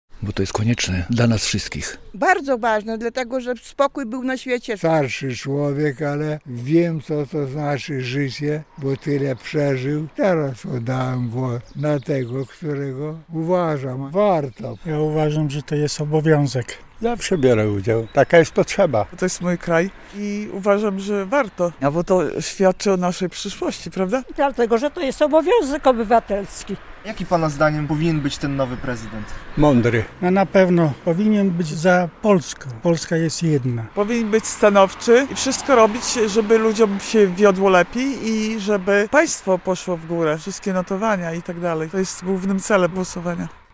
Dlaczego białostoczanie biorą udział w wyborach prezydenckich? - relacja